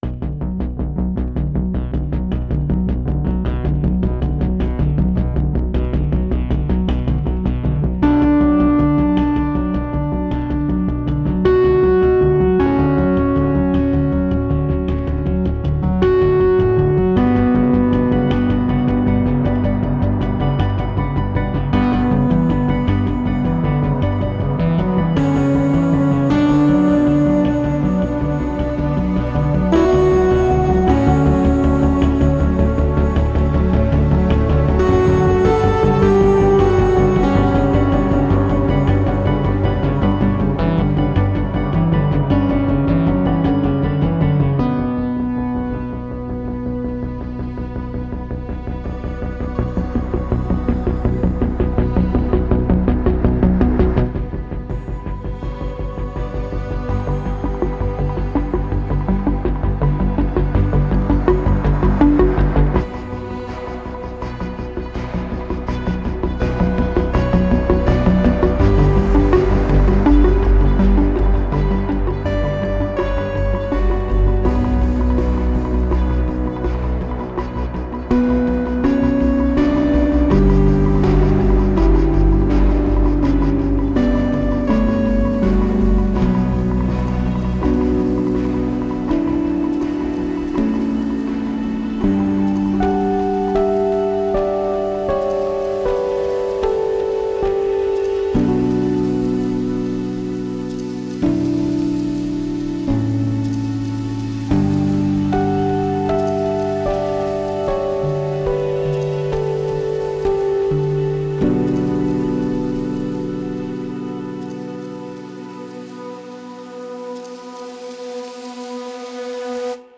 TECLADOS CINEMATOGRÁFICOS CÁLIDOS